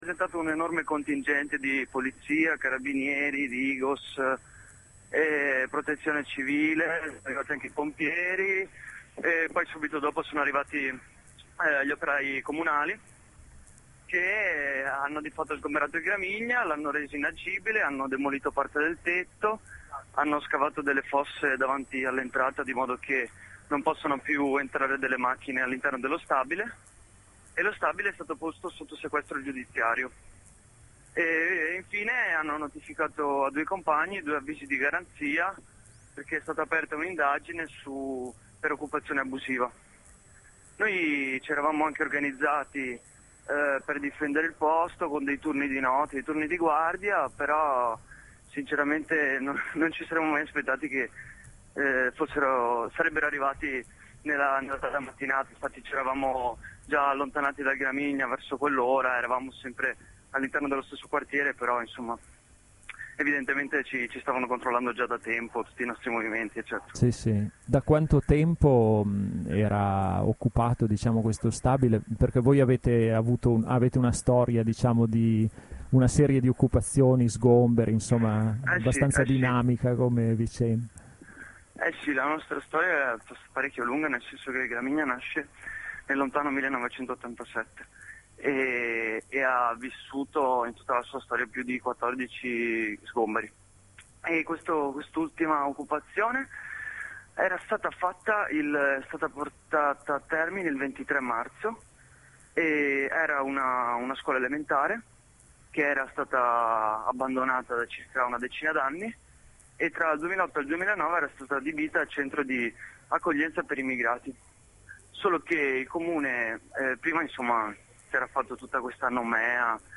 Ascolta la diretta con un compagno del Gramigna